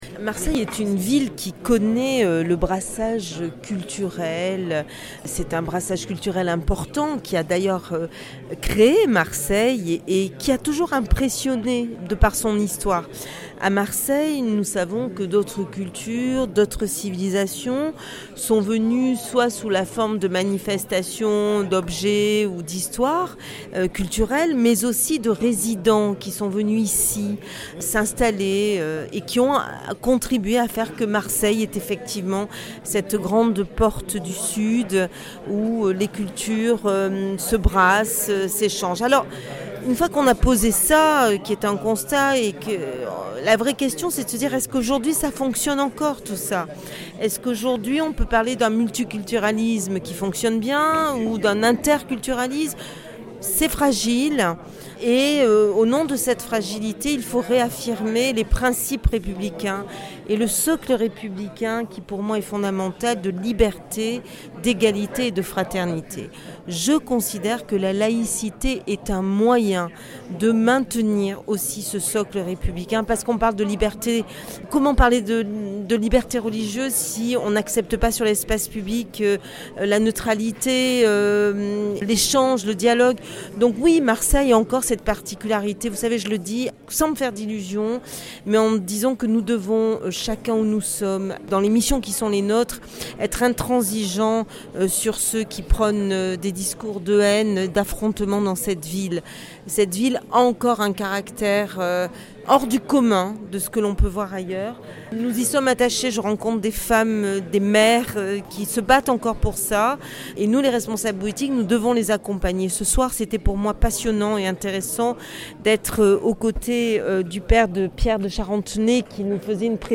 Marseille: riche débat à l’Institut Catholique de la Méditerranée sur ce que devient la laïcité
A la tribune, 3 personnalités dont deux qui ont témoigné de leur expérience de terrain pour la laïcité. son_copie_petit-99.jpgCaroline Pozmentier-Sportich, Adjointe au Maire de Marseille pour la sécurité et la prévention de la délinquance met en avant l’atout de Marseille.